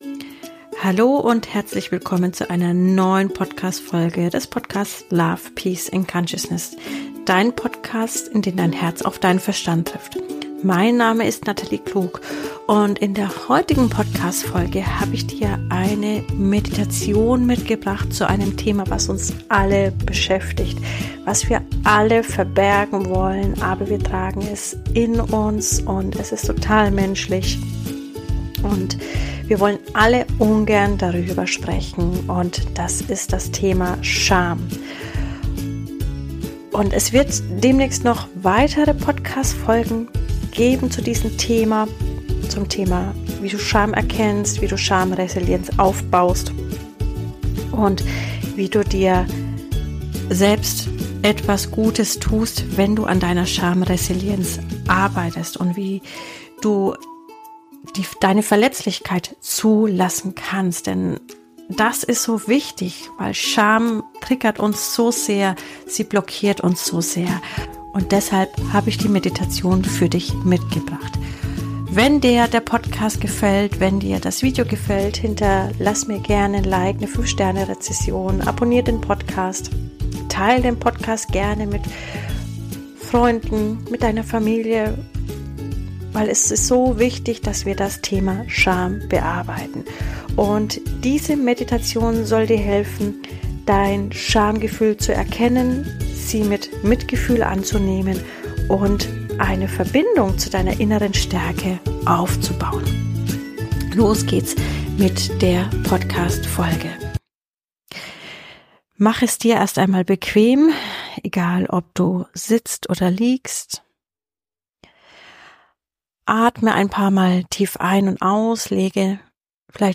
Scham erkennen, annehmen und loslassen Eine Meditation für innere Stärke.